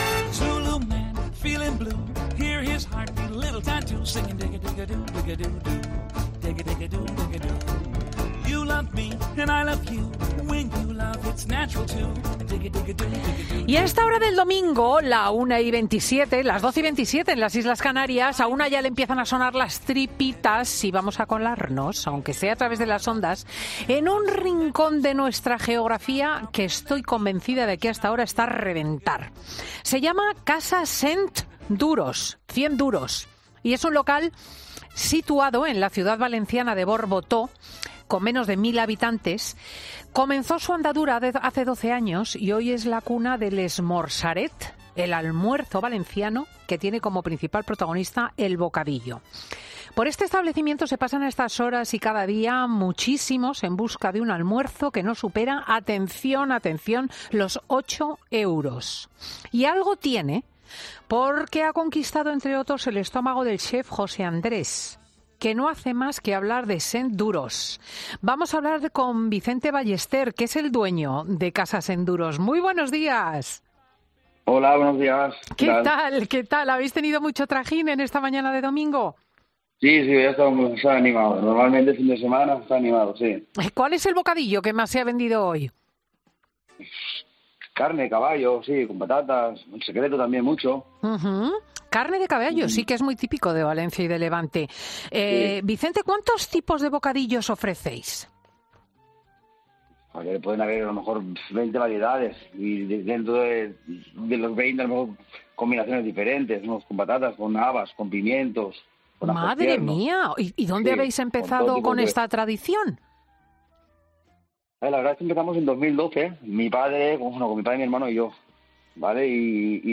En 'Fin de Semana' nos hemos colado, aunque sea a través de las ondas, en este local, que comenzó su andadura hace doce años y que recomienda hasta el chef José Andrés, que fue a visitarlo en primera persona.